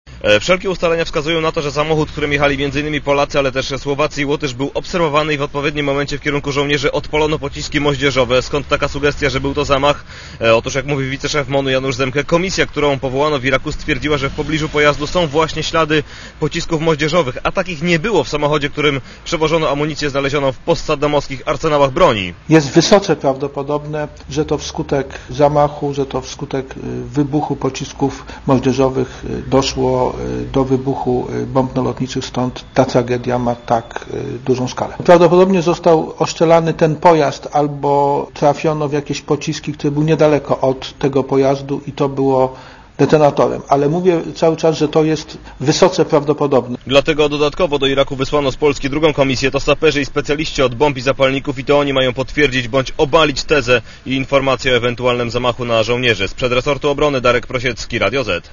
Z wiceszefem MON, Januszem Zemke rozmawiał